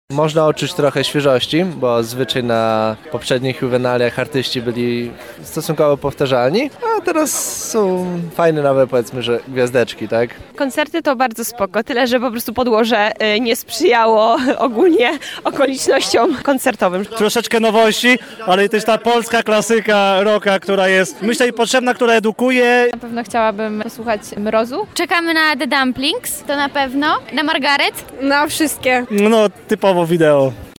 [SONDA] Jak mieszkańcy naszego miasta bawili się na Lublinaliach 2023?
[SONDA] Lublinalia 2023